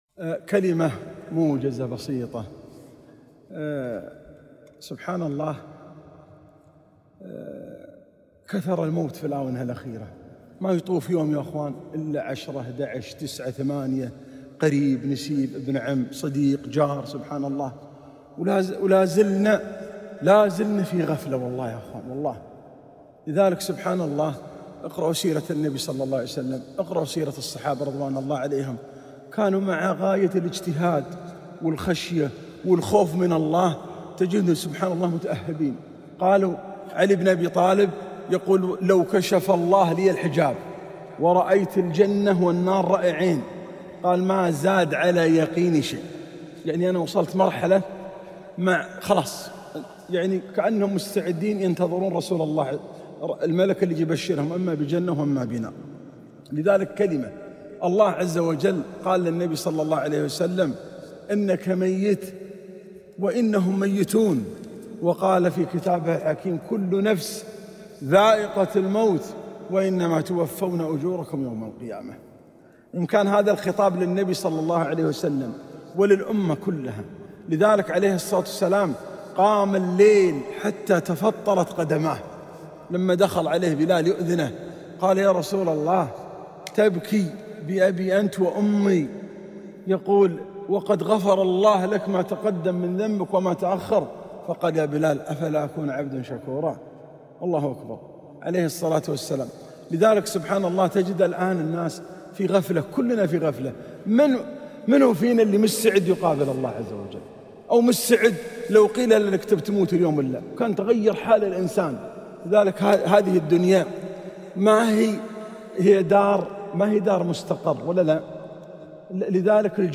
خاطرة - خوف الصحابة رضي الله تعالى عنهم من الله تعالى